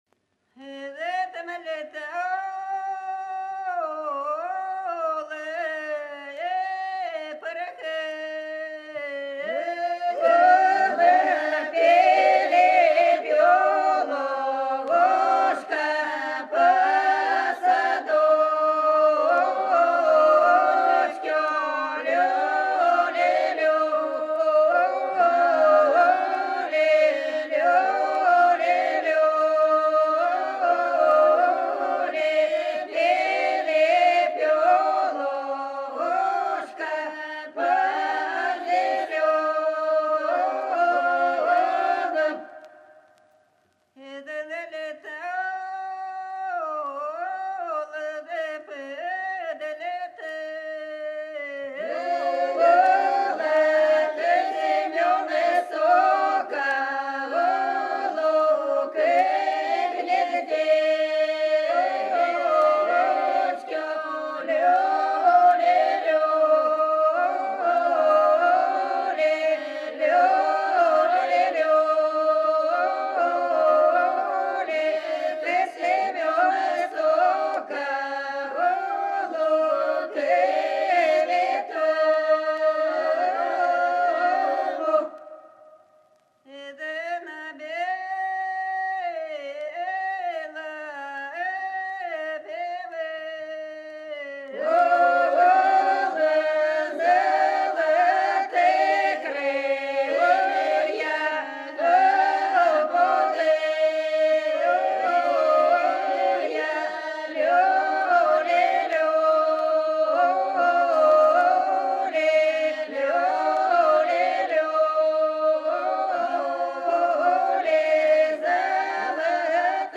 Раннее русское многоголосие
Там летала и порхала перепелушка (свадебная, с. Большебыково, Белгородская область)